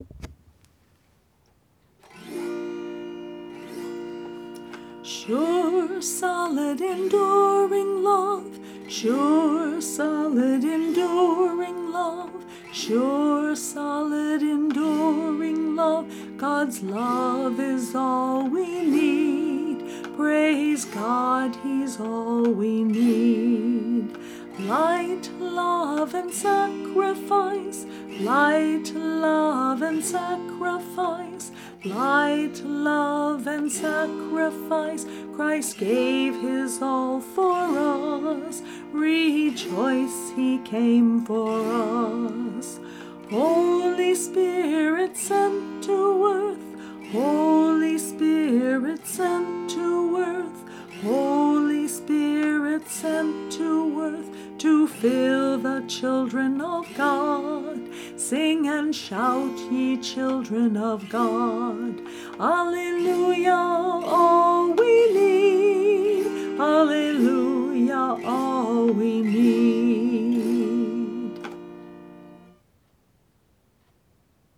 This entry was posted in Christian Music, Uncategorized and tagged , , , , , , , , , .